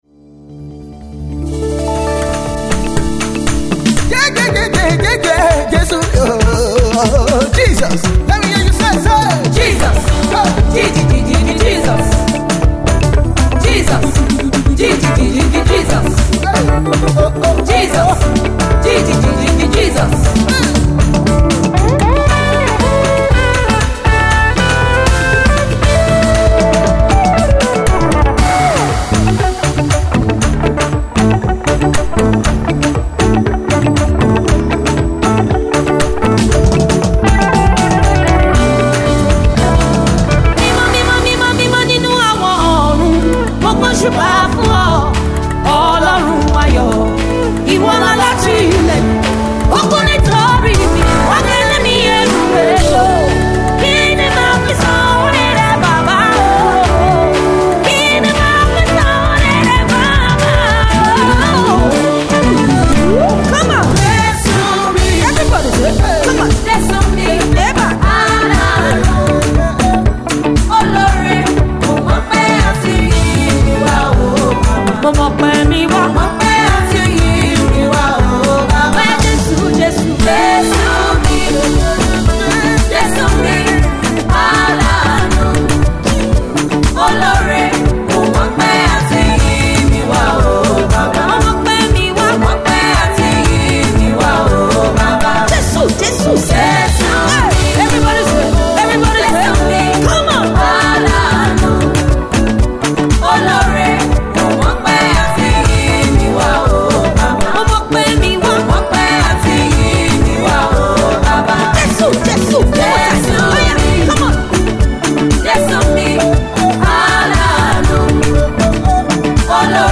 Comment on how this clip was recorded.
live video recording